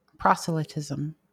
Proselytism (/ˈprɒsəlɪtɪzəm/